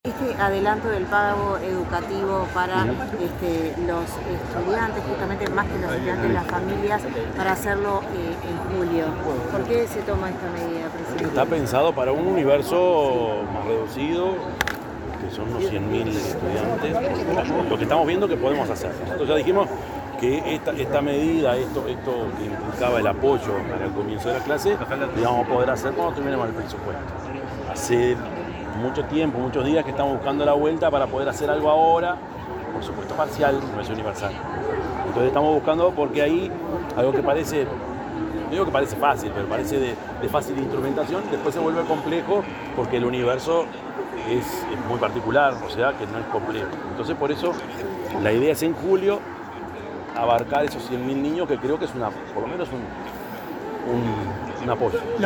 Declaraciones a la prensa del presidente de la República, Yamandú Orsi
El presidente de la República, profesor Yamandú Orsi, dialogó con los medios de prensa tras la inauguración de la Expo Uruguay Sostenible.